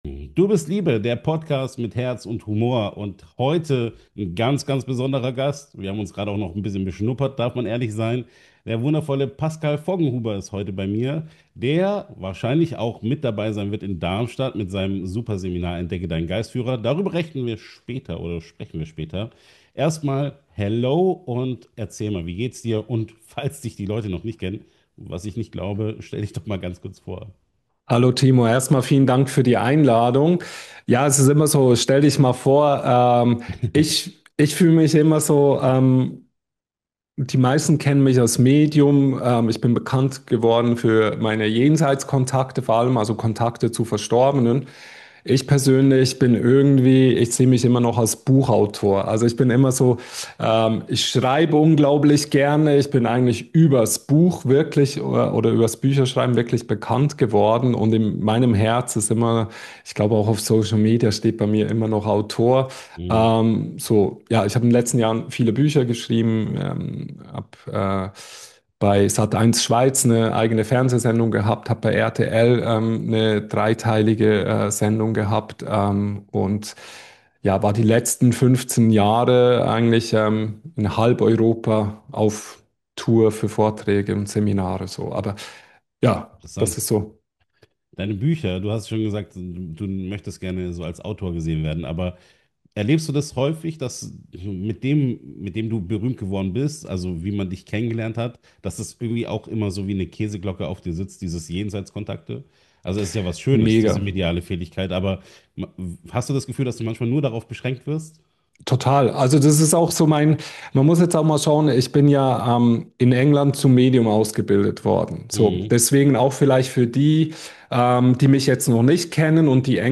In dieser Podcastfolge wird es humorvoll, ehrlich und herrlich unzensiert.
Ein Talk zwischen Tiefe und Lachen, Ernsthaftigkeit und Selbstironie – genau so, wie Spiritualität auch sein darf.